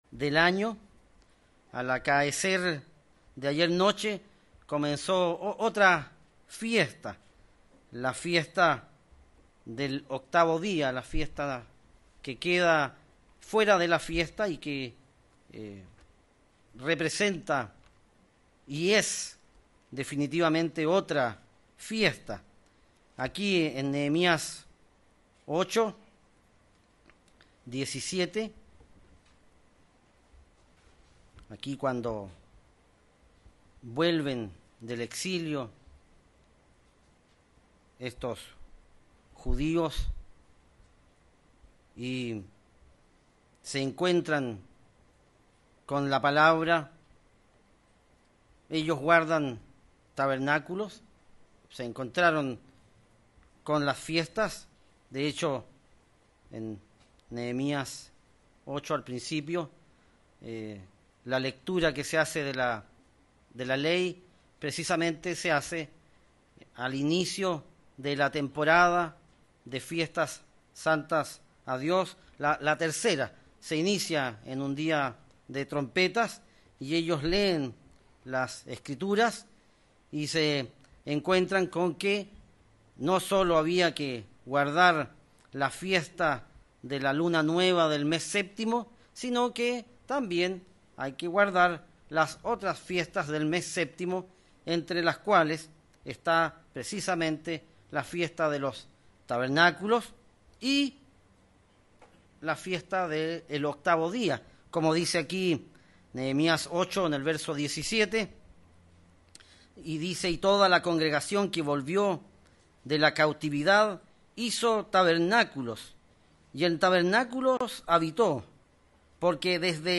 ¿Por qué la Fiesta del Octavo día se considera diferente de Tabernáculos? La Biblia nos explica las diferencias, en el marco del Plan de Salvación de Dios. Mensaje entregado el 21 de octubre de 2019.